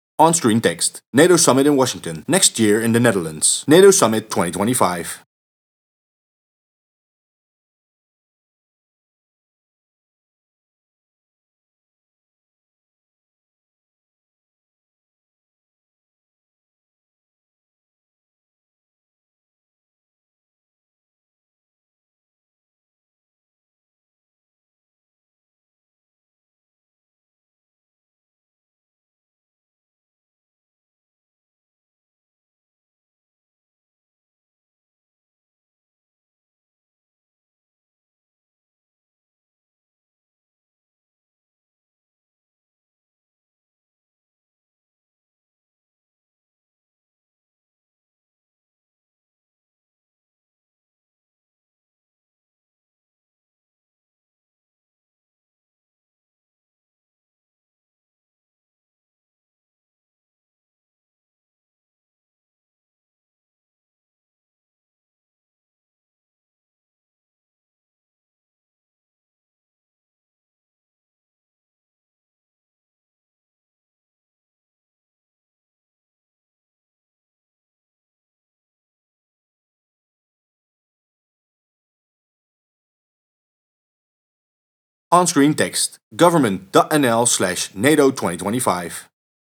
*Background music*